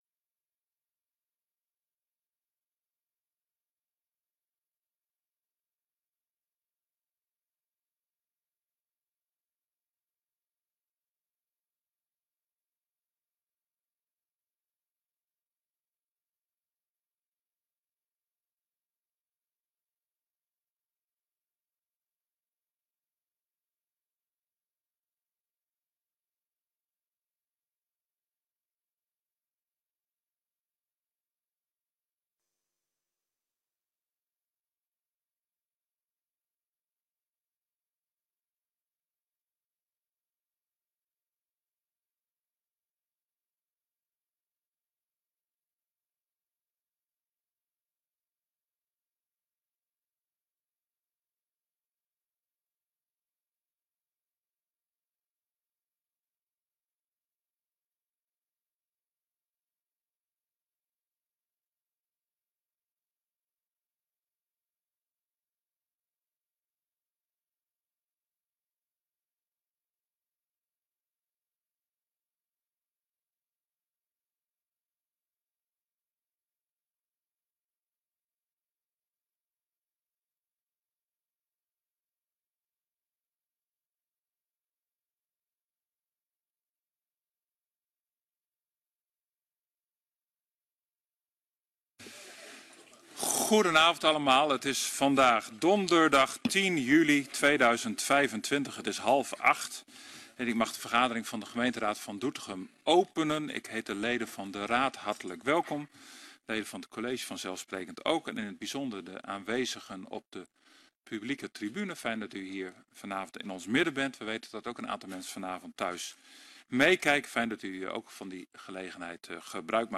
gemeenteraad 10 juli 2025 19:30:00, Gemeente Doetinchem
Locatie: Raadzaal Voorzitter: M. Boumans, burgemeester